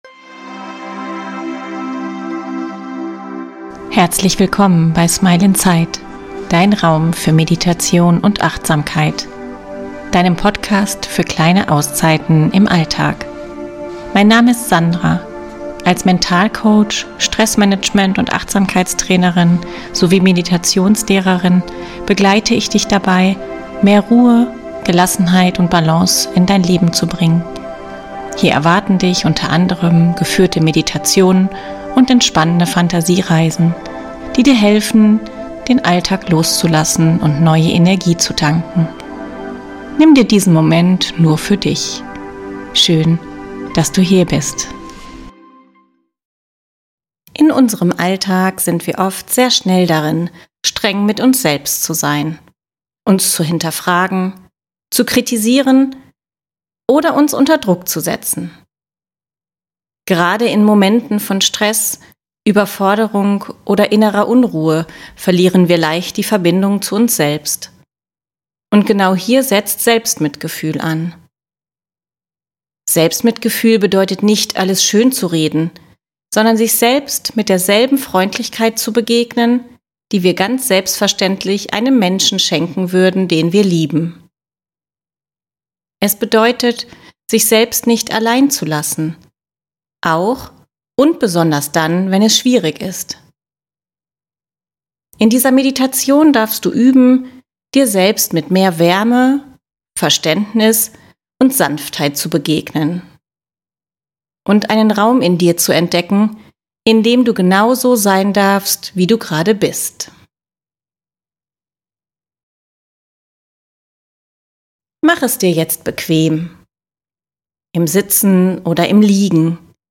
In dieser geführten Meditation begleite ich dich dabei, wieder in Kontakt mit dir zu kommen und dir selbst auf eine freundliche, achtsame Weise zu begegnen.